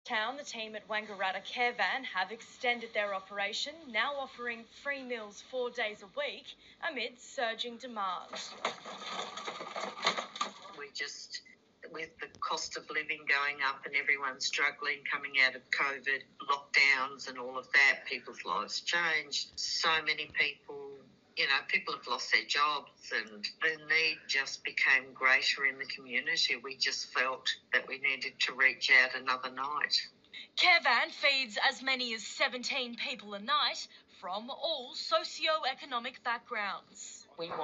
Just an snip from the boarder News tonight about Carevan now operating 4 nights a week.